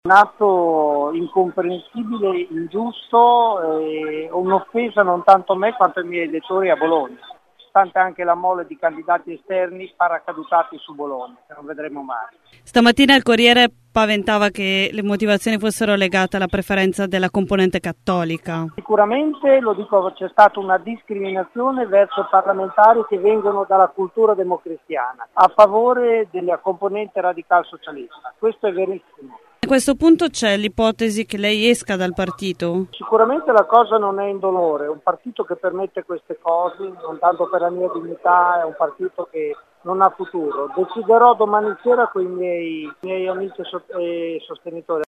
Ascolta Fabio Garagnani